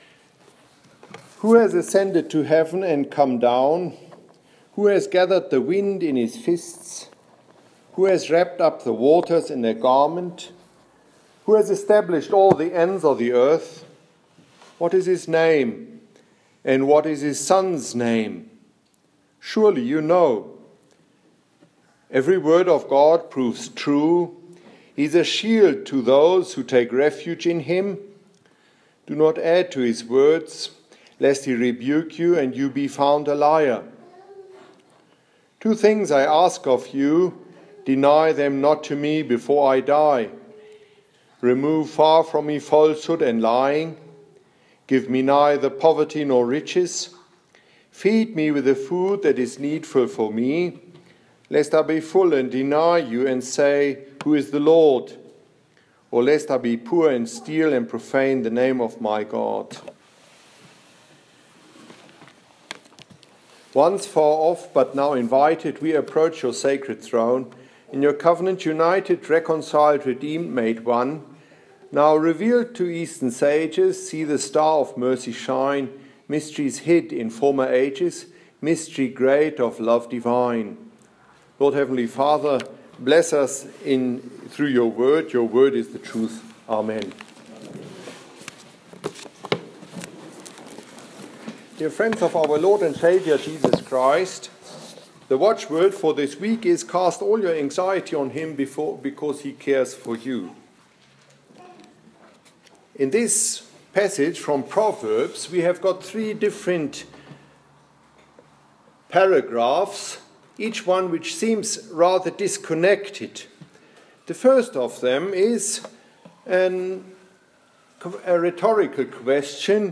Here’s this mornings sermonette preached in St.Timothy Matins Proverbs 30,4-9 and you can listen to it too: